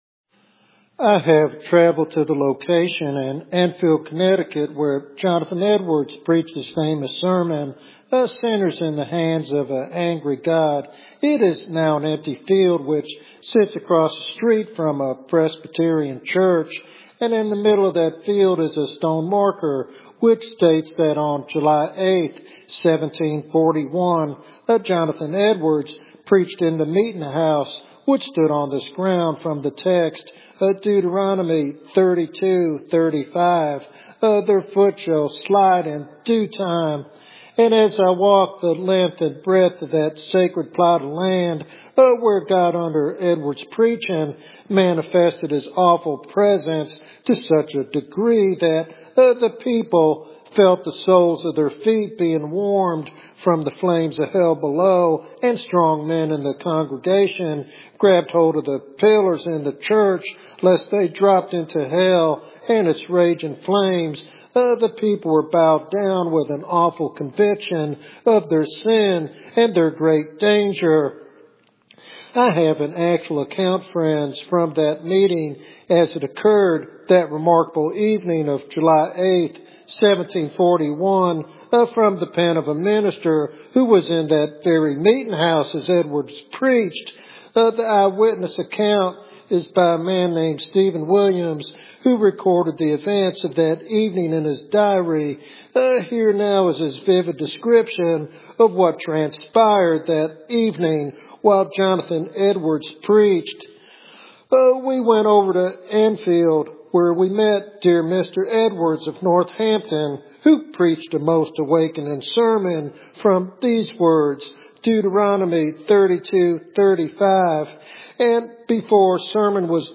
He urges listeners to repent and trust in Jesus for salvation, warning of the grave danger of false security. This sermon is a clarion call for revival through faithful preaching of God's holiness and justice.